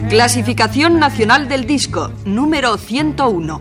Identificació del programa